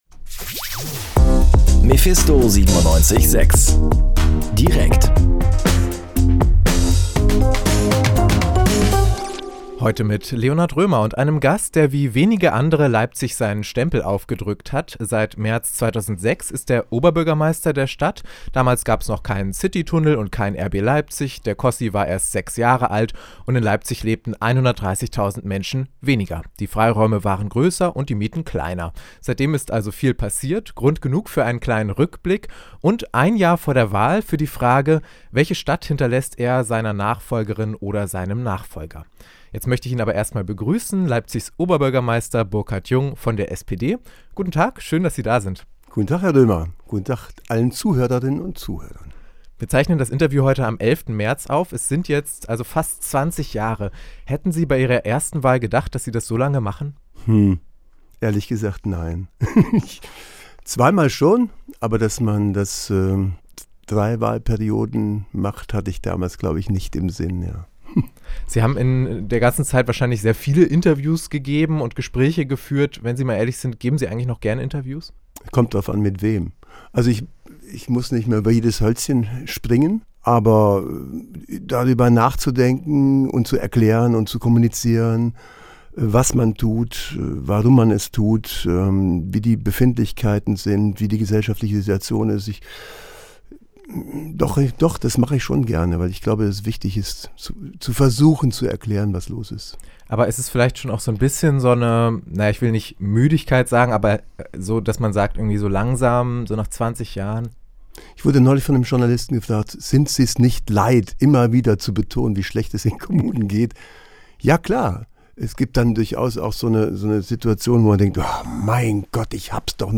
Oberbürgermeister Burkhard Jung spricht im langen Interview mit mephisto 97.6 über Persönliches und Politik. Pünktlich zum 20. Amtsjubiläum spricht er unter anderem über steigende Mieten, den Aufschwung extremer Parteien und den Klimaschutz.